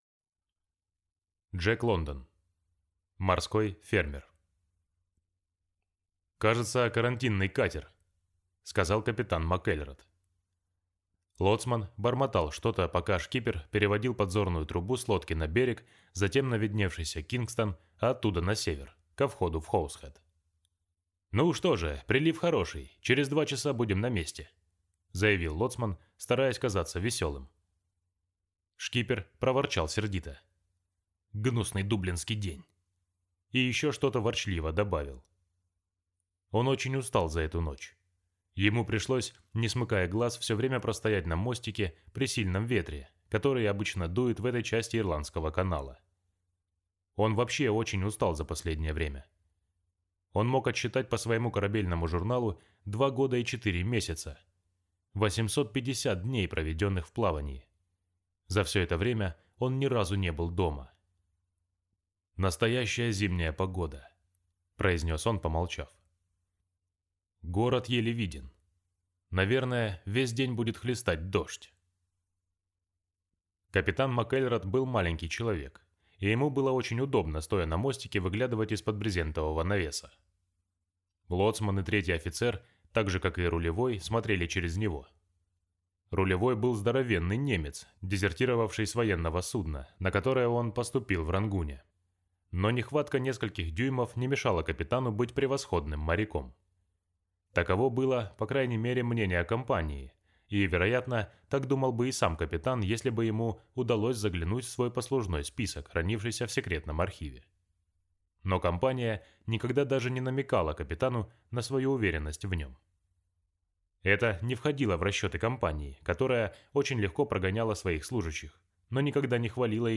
Аудиокнига Морской фермер | Библиотека аудиокниг